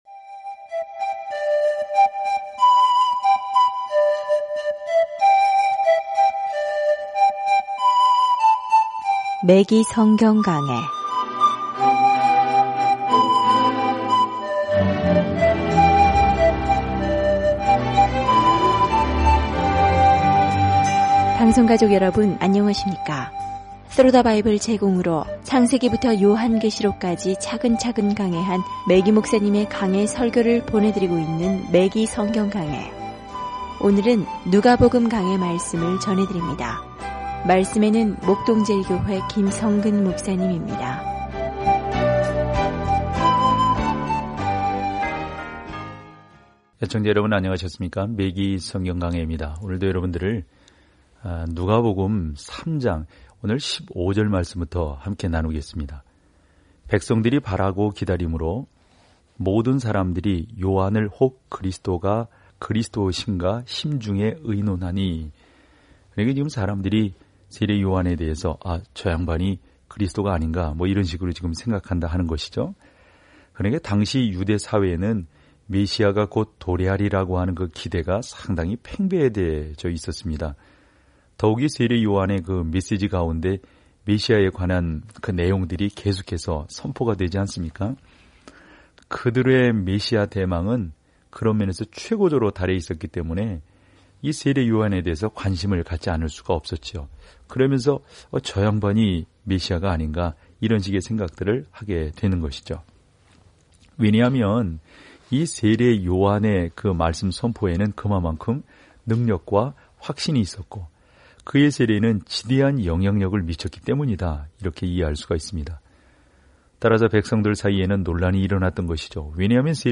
누가는 또한 세상을 변화시킨 그분의 가르침을 다시 이야기합니다. 오디오 공부를 듣고 하나님의 말씀에서 선택한 구절을 읽으면서 매일 누가복음을 여행하세요.